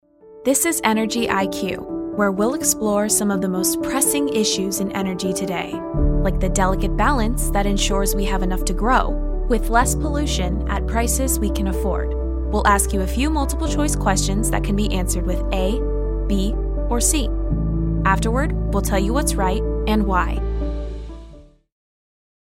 Female Voice Over, Dan Wachs Talent Agency.
Sincere, Animated, Real, Youthful.
Corporate